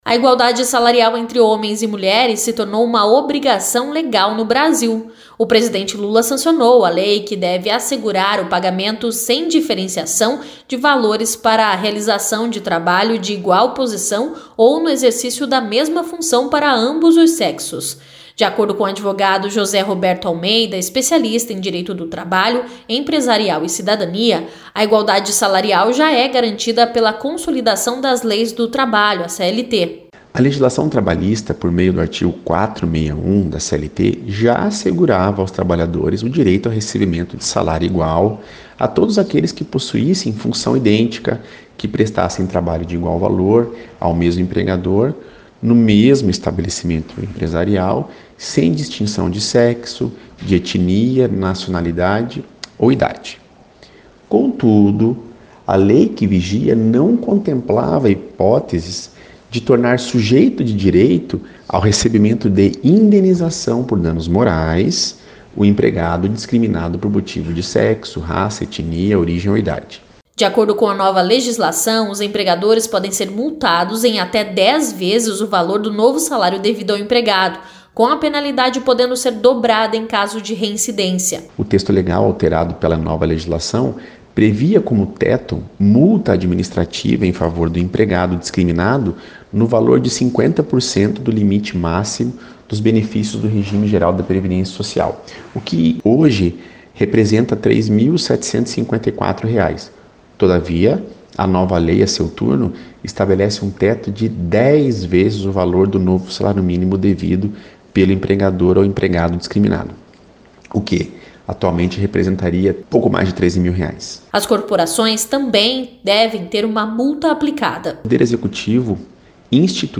Advogado especialista fala sobre os impactos e implicações da nova lei.